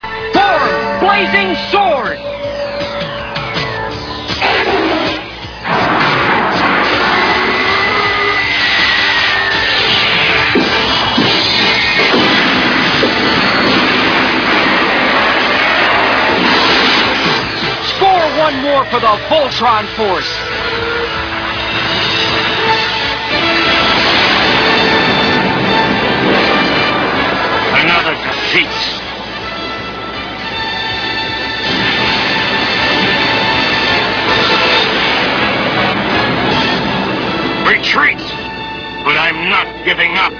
Form BlaZing Sword ....slash, wack,